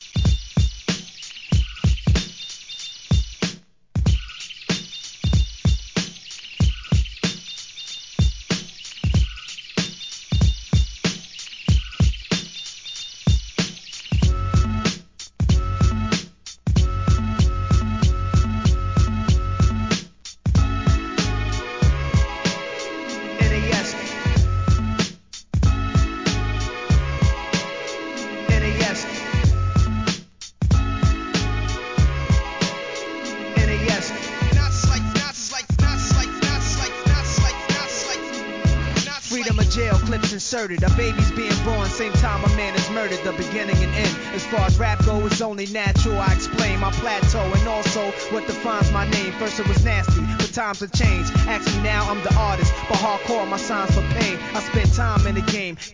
HIP HOP/R&B
(BPM 94.5)